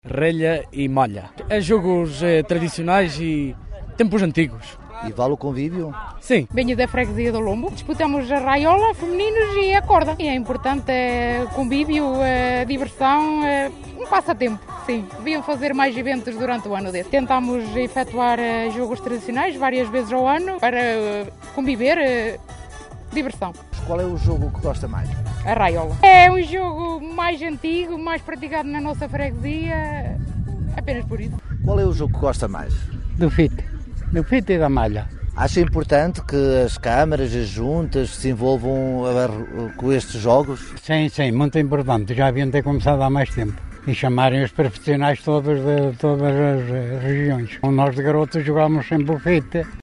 Quem participou disse ter sido uma oportunidade de recordar tempos antigos:
vox-pop-jogos-tradicionais.mp3